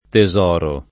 tesoro [ te @0 ro ]